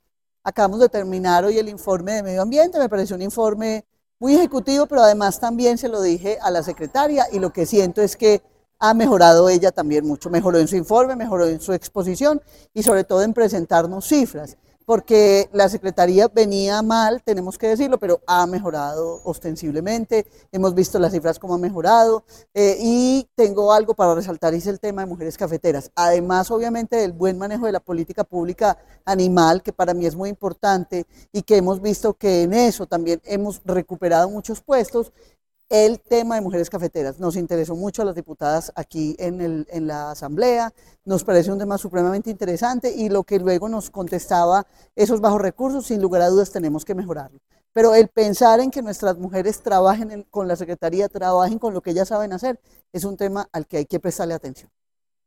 María Isabel Gaviria Calderón, diputada de Caldas.
Maria-Isabel-Gaviria-Calderon-diputada-de-Caldas.mp3